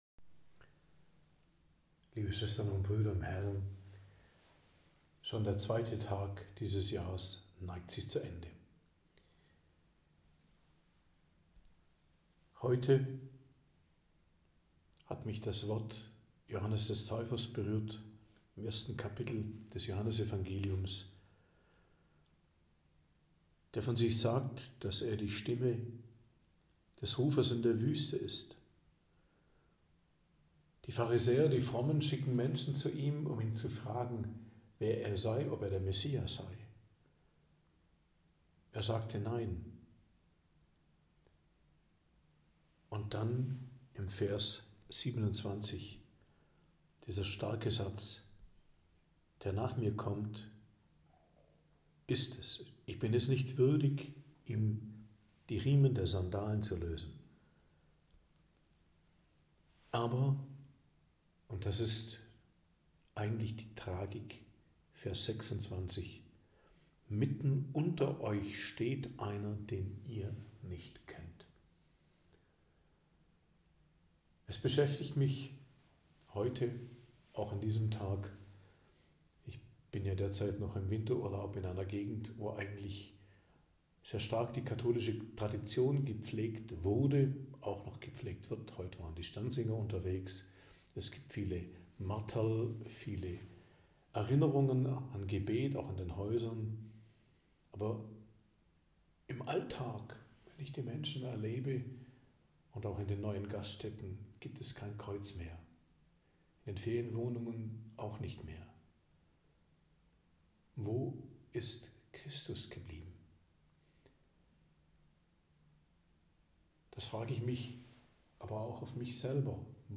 Predigt am 2. Januar, 2.01.2025